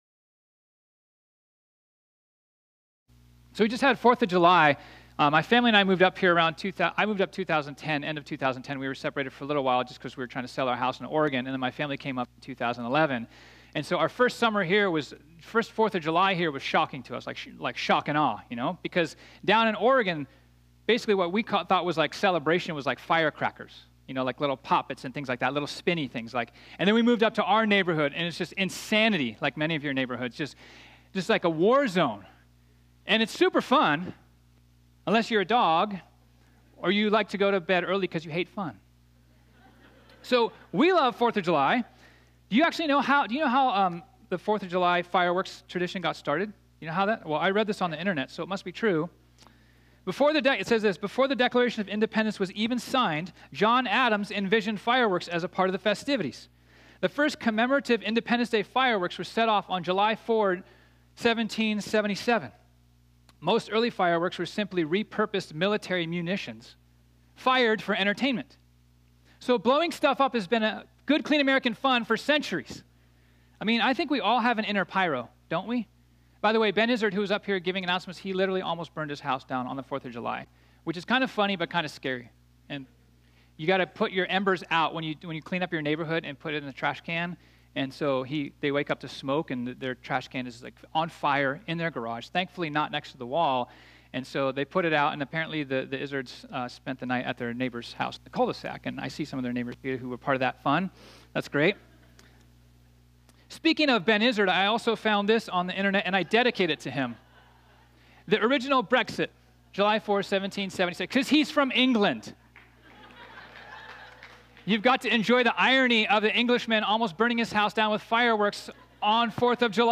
This sermon was originally preached on Sunday, July 7, 2019.